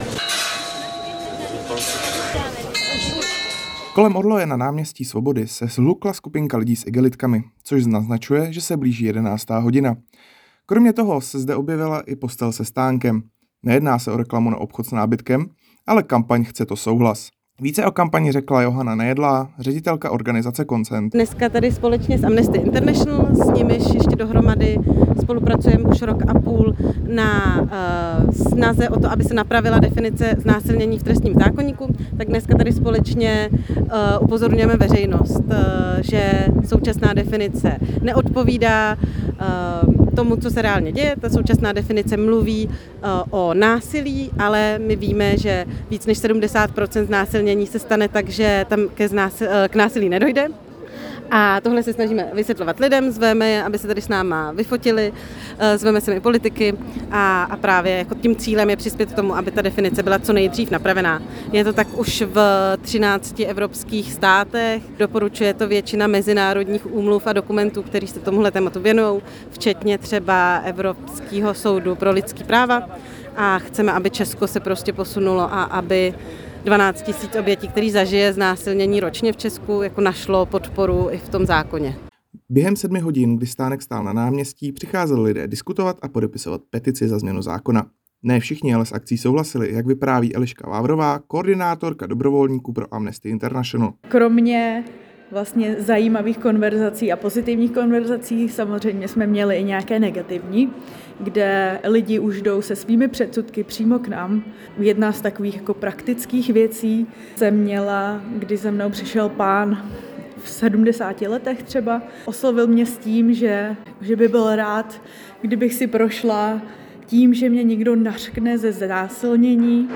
reportáž